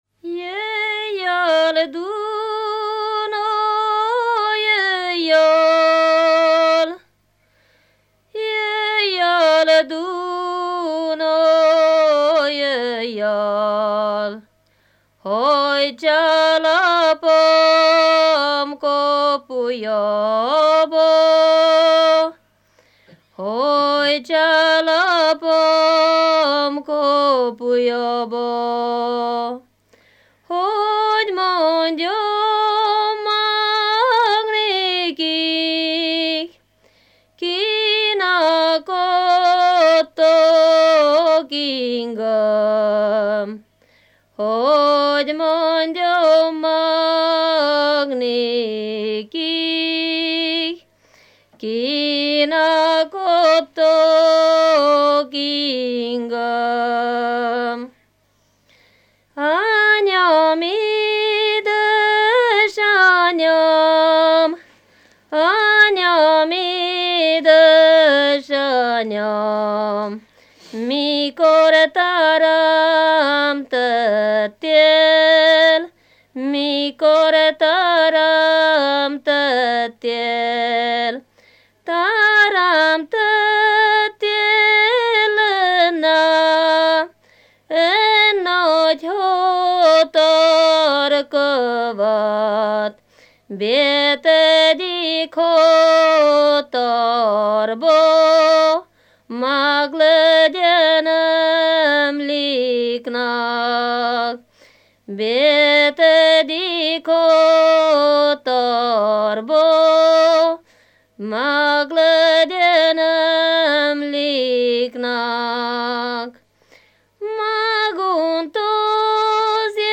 ének
ballada
Bogdánfalva
Moldva (Moldva és Bukovina)